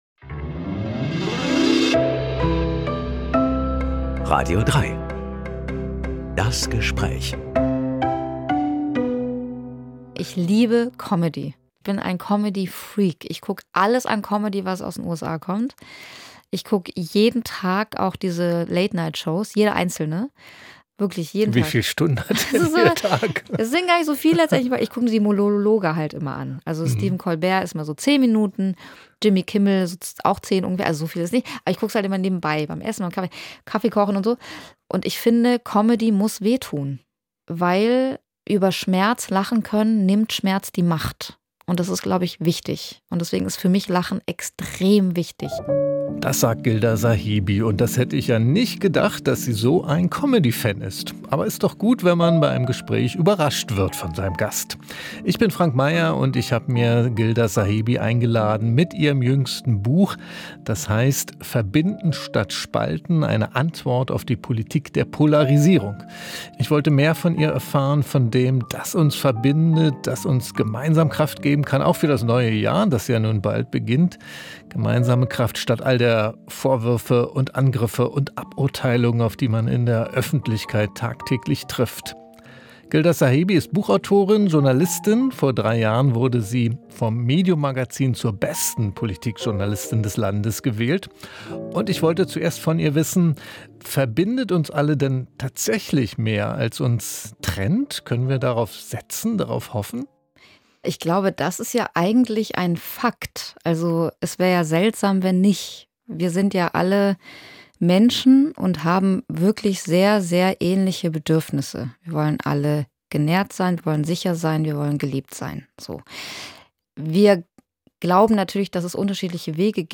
Journalistin ~ Das Gespräch Podcast